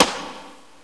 soft-hitclap.wav